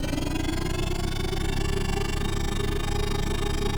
Speed_loop_7.ogg